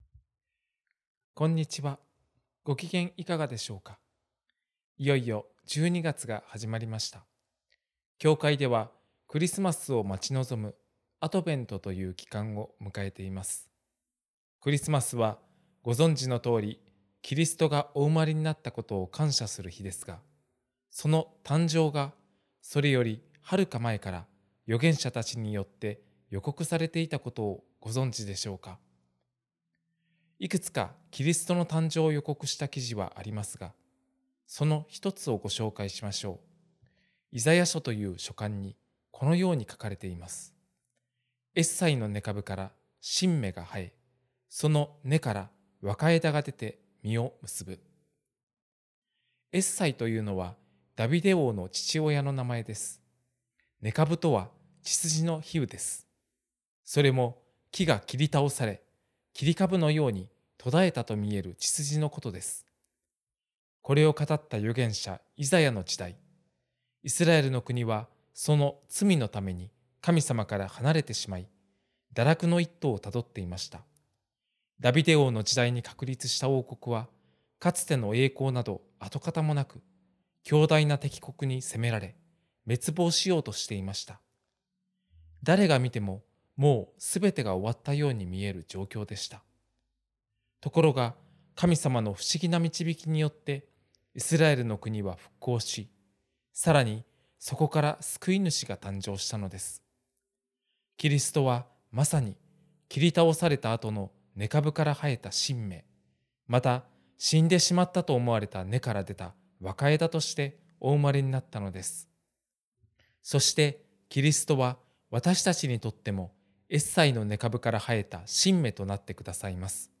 電話で約３分間のテレフォンメッセージを聞くことができます。